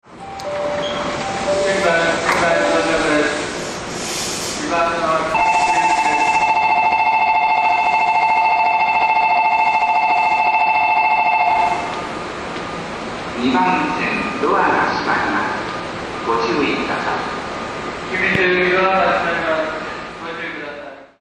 地下
ベル --